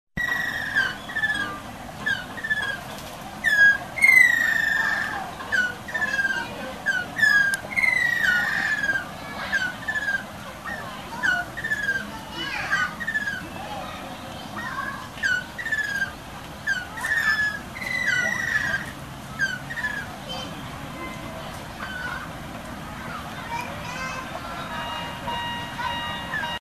奈良信用金庫CSR活動：春日山原始林保全プロジェクトのレポート「アカショウビンの声が聴けます ♪」を掲載しています。
よく響く声で鳴きますが、見つけるのは難しいです。春日原始林でも、この"キョロロロロー" と響きわたる声を聴きたいものです。
アカショウビンの声 ← クリックして下さい ♪ アカショウビンの動画 ← クリックして下さい ♪ 撮影場所 ： 京都市動物園 （京都市左京区 岡崎公園内） アカショウビンは、体全体が鮮やかな赤褐色で特に赤のクチバシが目立ち火の鳥と呼ばれることも。